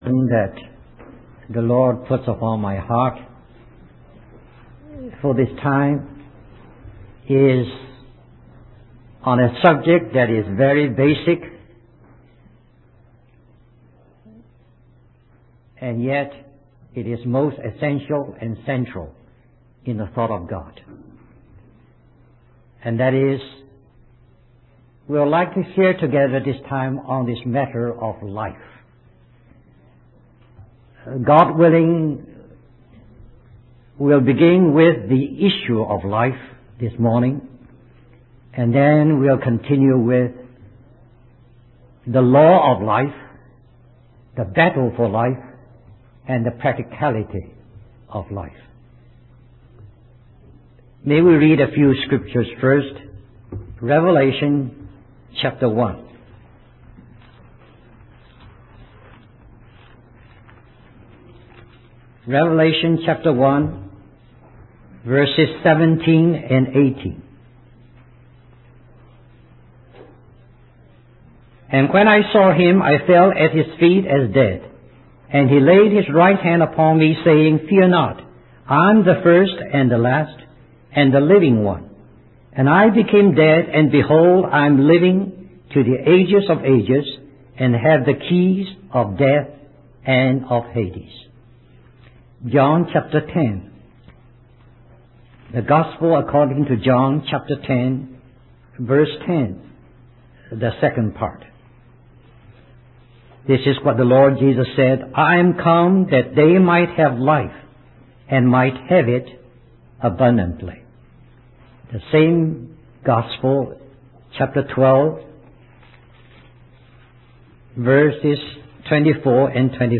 In this sermon, the preacher emphasizes the hopeless situation of mankind due to sin and the inability to regain access to the tree of life. However, the preacher highlights that God's love for the world led him to send his only begotten son, Jesus, to bring life back to humanity.